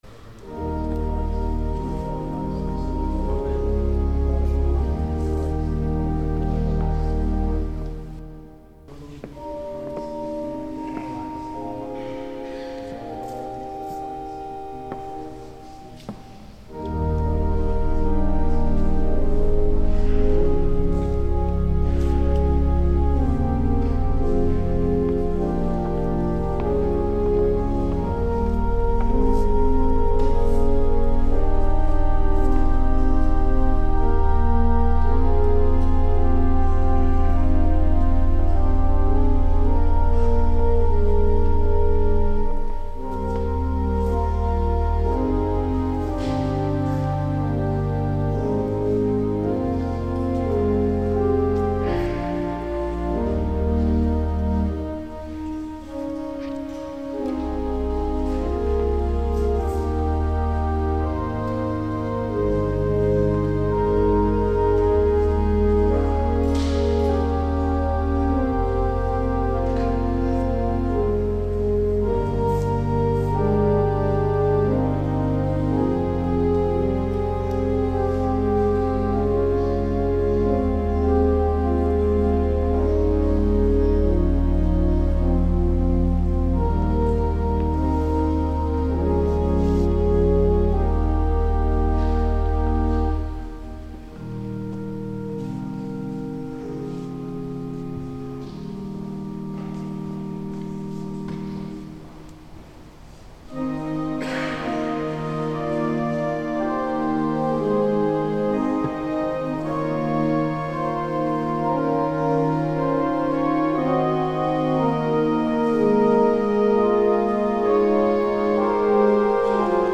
Organ - Samuel Walter - Eucharistic Hymn